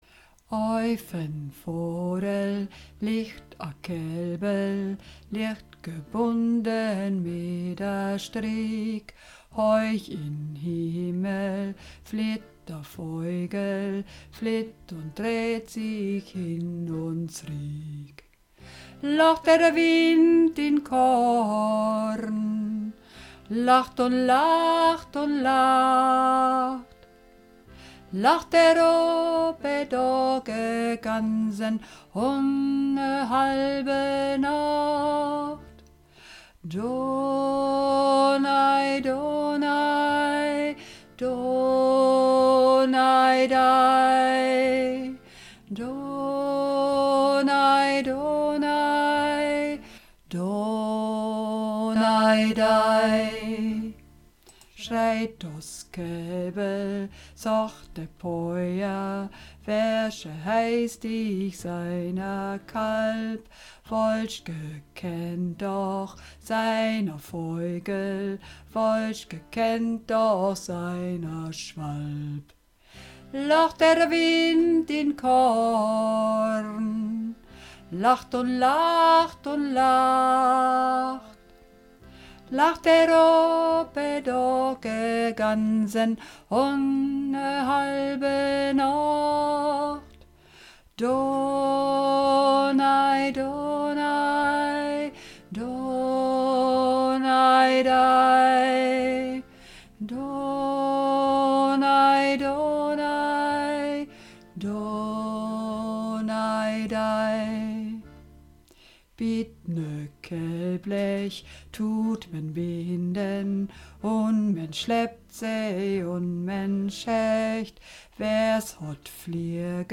Runterladen (Mit rechter Maustaste anklicken, Menübefehl auswählen)   Dos Kelbl (Donaj Donaj) (Alt - nur Stimme)
Dos_Kelbl_Donaj_Donaj__1a_Alt_Nur_Stimme.mp3